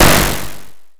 8bit SFX
Explode_03.mp3